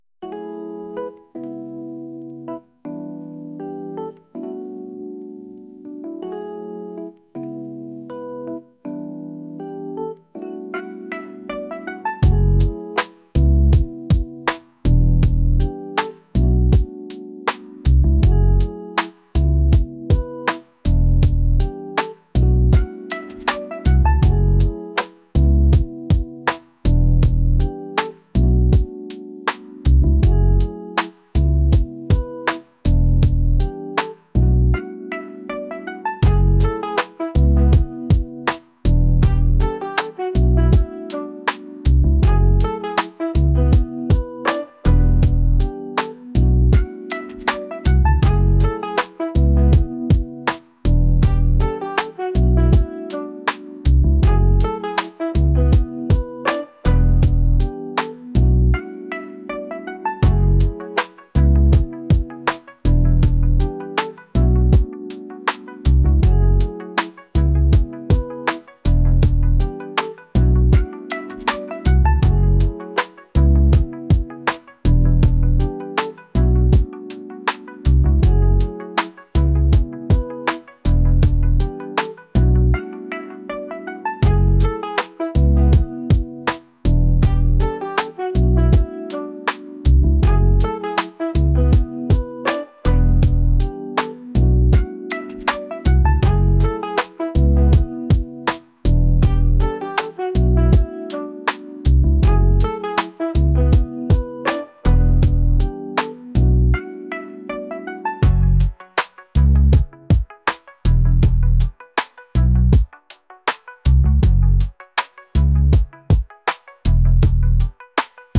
rap | soul & rnb | laid-back